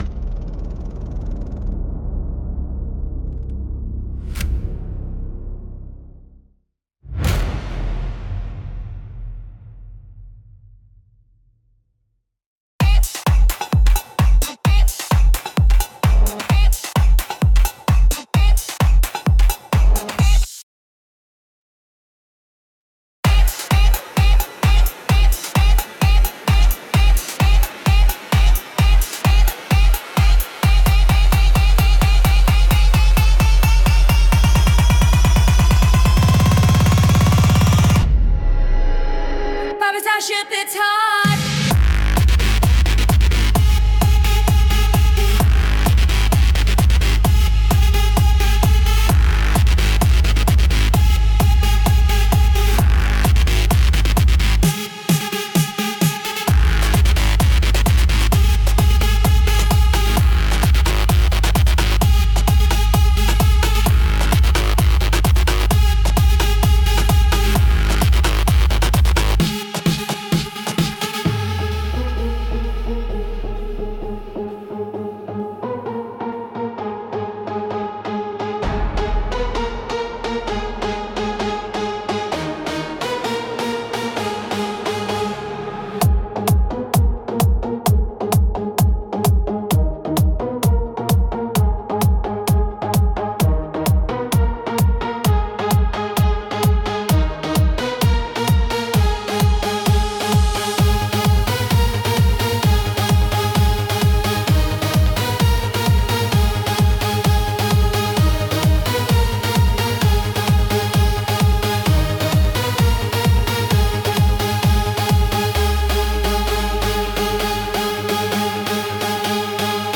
Instrumental - JOIN Real Liberty media Platforms TODAY - 3.40 !!